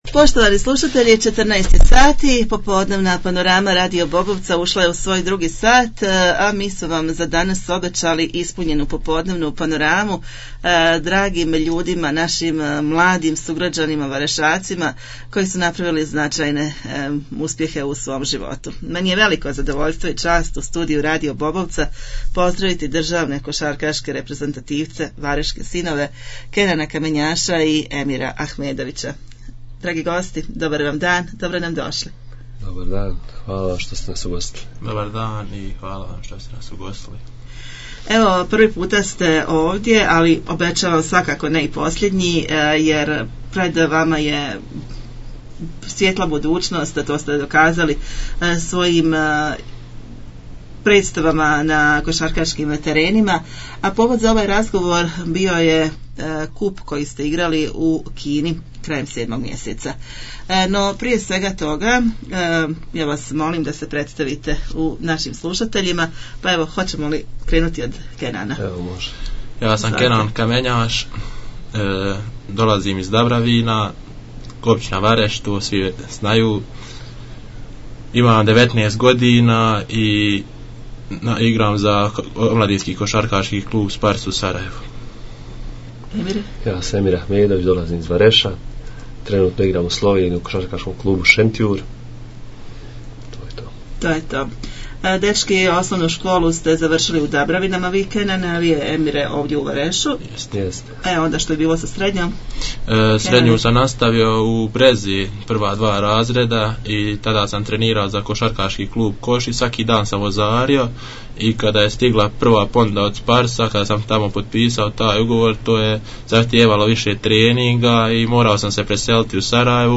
Kosarkasi BiH gosti u našem studiju